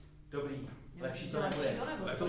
Nahrávali jsme ve Spálově v ZUŠce na jaře 2019.